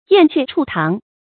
注音：ㄧㄢˋ ㄑㄩㄝˋ ㄔㄨˇ ㄊㄤˊ
燕雀處堂的讀法